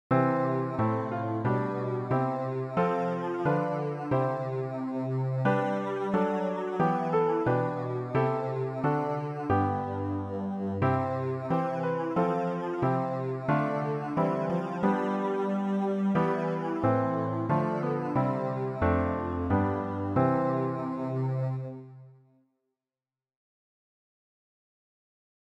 bass-rg-257-walte-walte-nah-und-fern.mp3